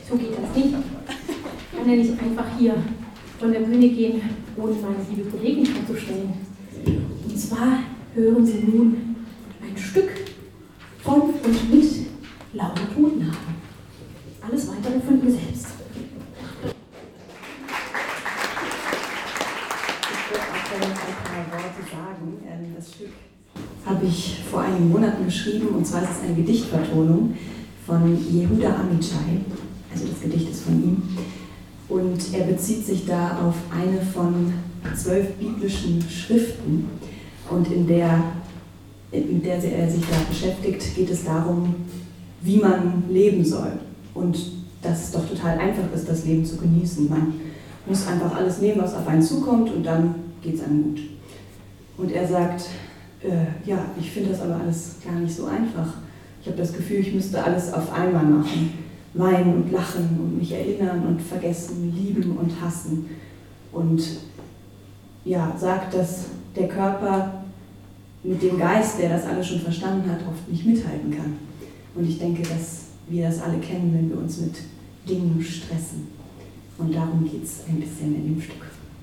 14 - Ansage.mp3